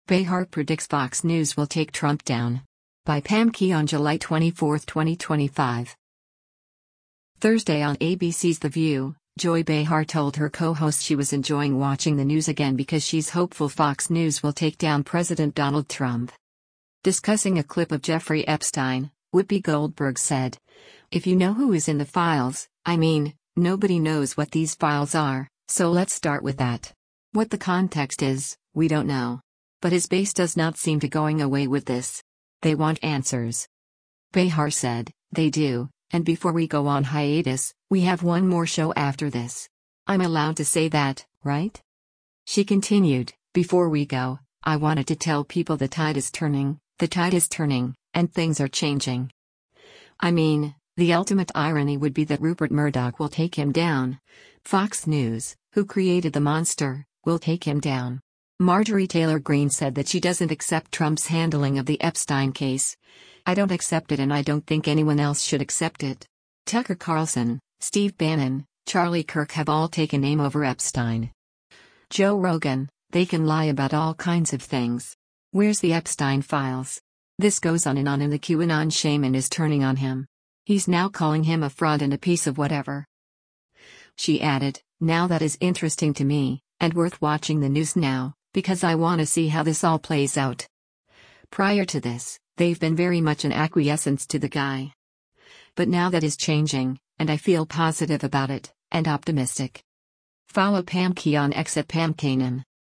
Thursday on ABC’s “The View,” Joy Behar told her co-hosts she was enjoying watching the news again because she’s hopeful Fox News will take down President Donald Trump.